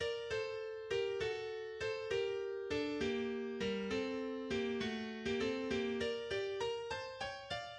Symphonia domestica (published in 1904 as SYMPHONIA DOMESTICA and in 1926 as Symphonia domestica), Op. 53, is a tone poem for large orchestra by Richard Strauss reflecting the secure domestic life valued by the composer and accordingly dedicated by him to his "dear wife and young ones."
Scherzo
Cradle song [quotation from Felix Mendelssohn's "Venetian Boat Song", Op. 19b, No. 6 from Songs Without Words] (The clock strikes seven in the evening).